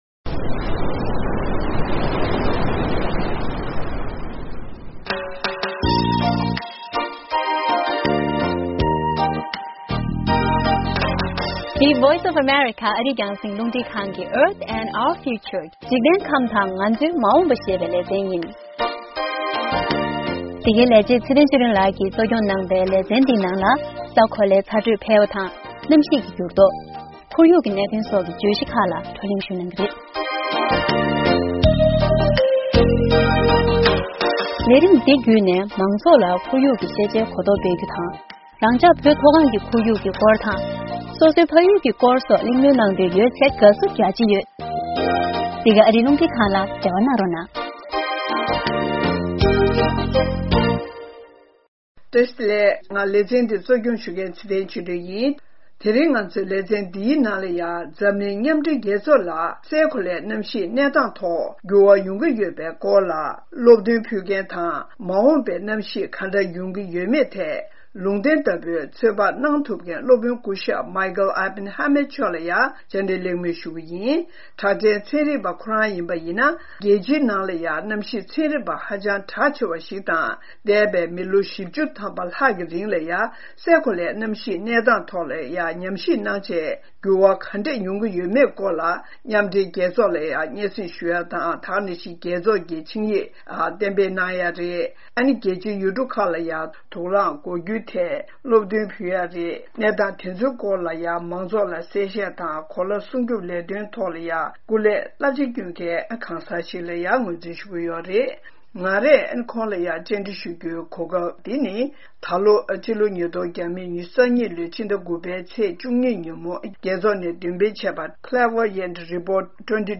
Earth & Our Future: Interview with world famous Climate Scientist: Dr. Michael Oppenheimer of Princeton University.